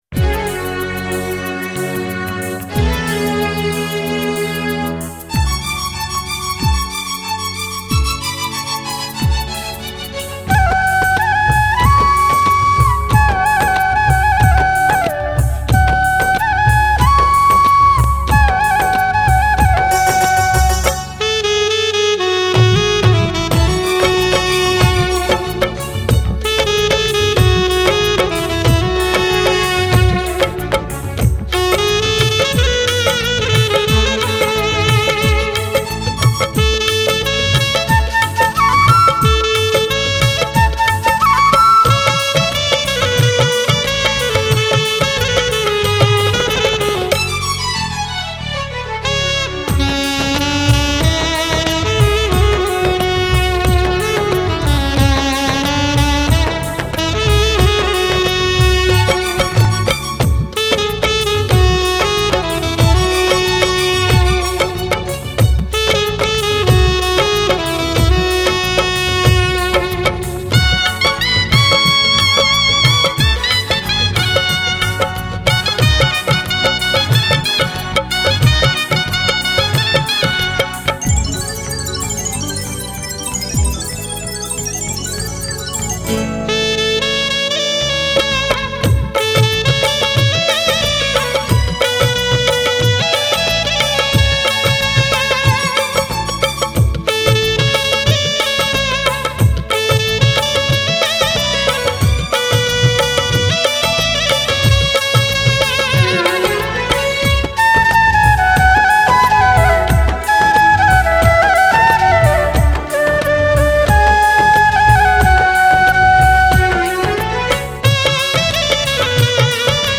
Category: Odia Karaoke instrumental Song